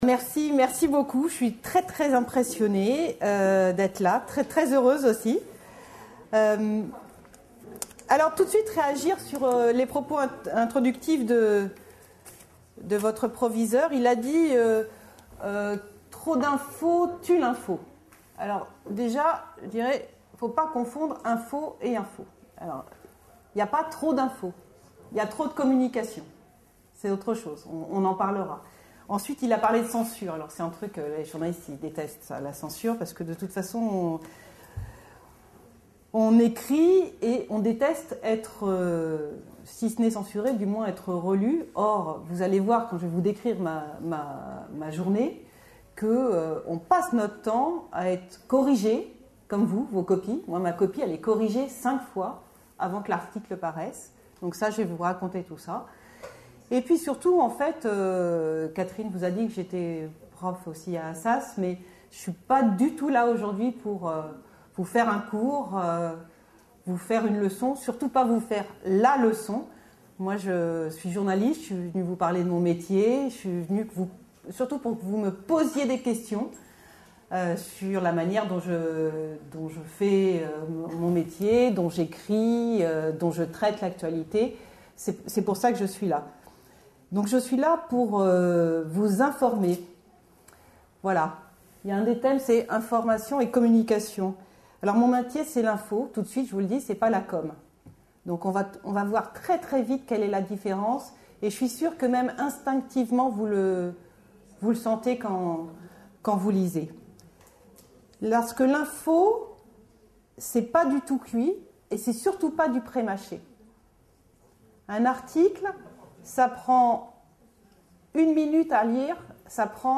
Une conférence de l'UTLS au Lycée La presse : qui fait l'info ?